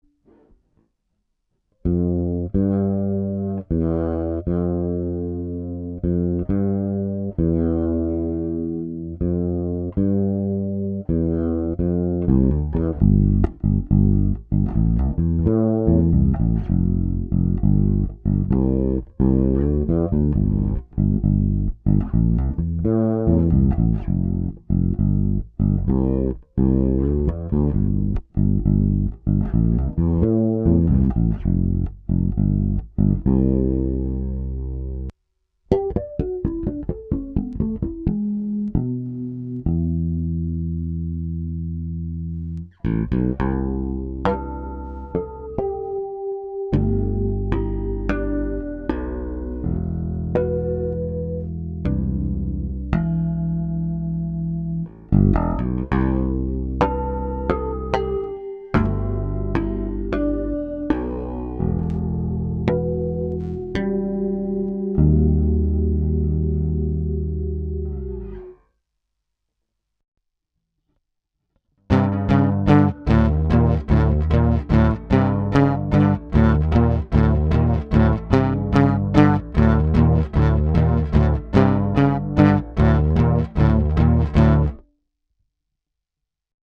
Ich hab mit dem 220,- Bass mal flugs etwas Gedudel aufgenommen. Und mein Uralt Boss Chorus kommt nach Jahrzehnten auch mal zum Einsatz.